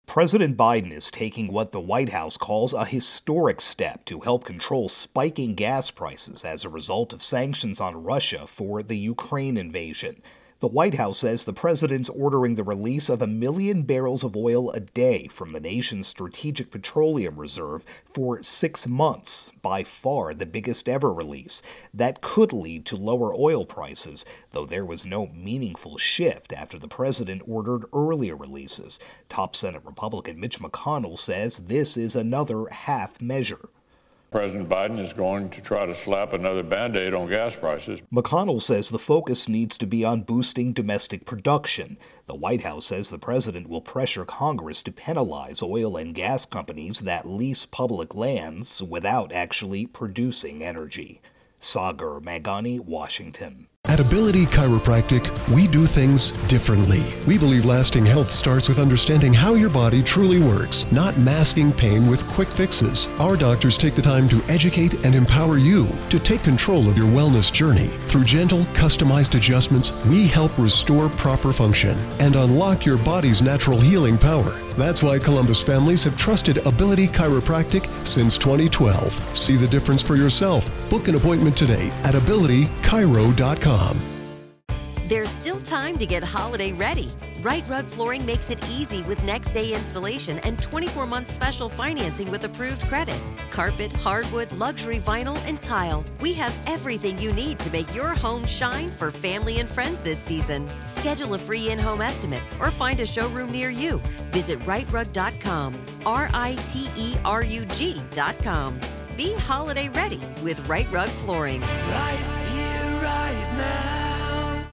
reports on Biden-Oil Reserve.